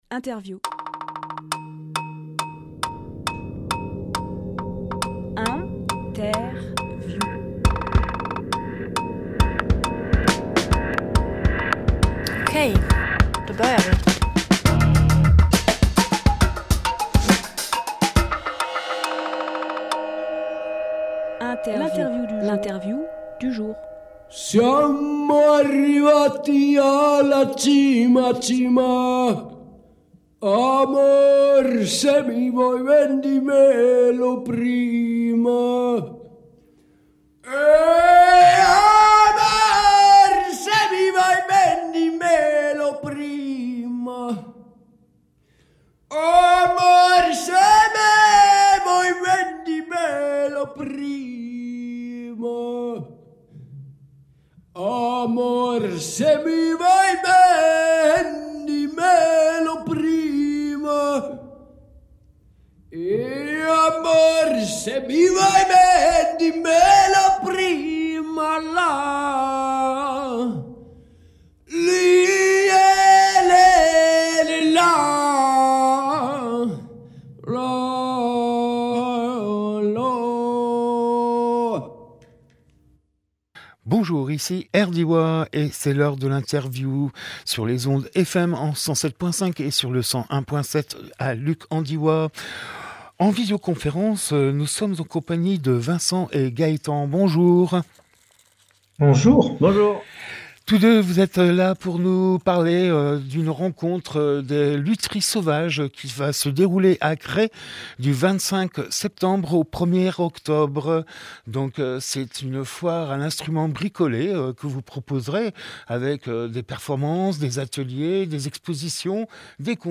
Emission - Interview Micro contact, une exploration des sons Publié le 21 septembre 2023 Partager sur…
Lieu : Studio RDWA